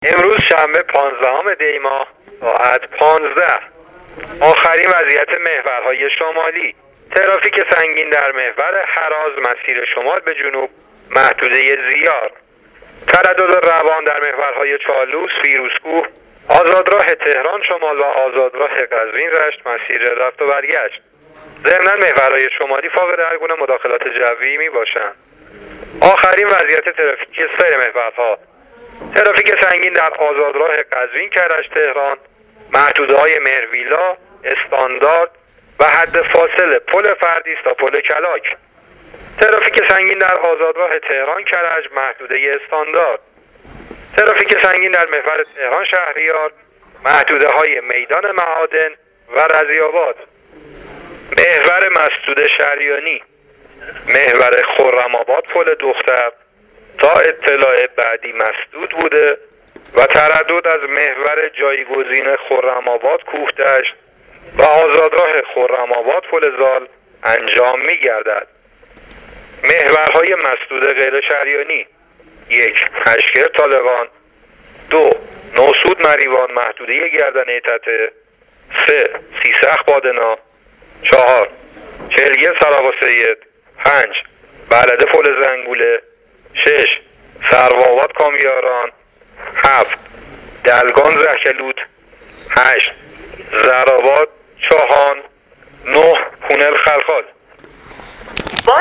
گزارش رادیو اینترنتی از آخرین وضعیت ترافیکی جاده‌ها تا ساعت ۱۵ پانزدهم دی؛